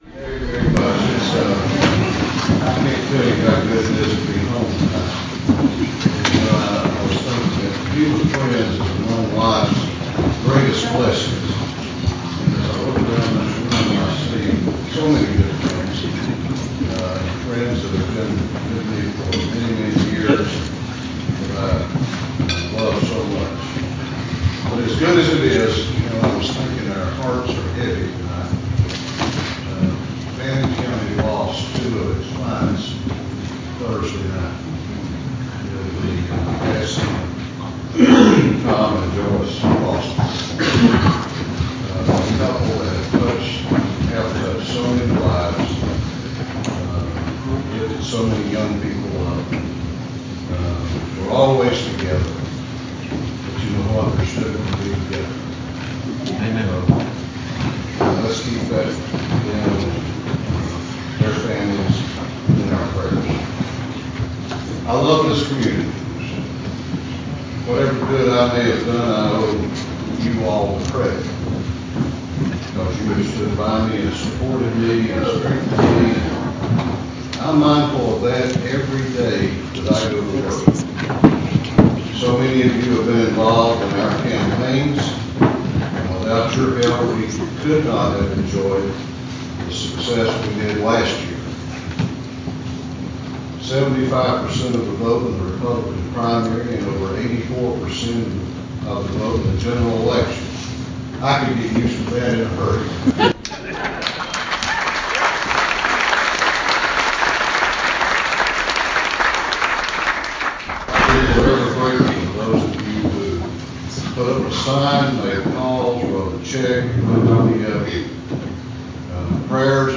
Speaker David Ralston’s Remarks At The Fannin GOP Valentine’s Dinner
Georgia House Speaker David Ralston gave remarks at the annual Fannin County GOP Valentine’s Day dinner this past Saturday evening. In those remarks, he talked about the successes of the Republican Party, but he also gave folks a reminder of what’s coming in 2020.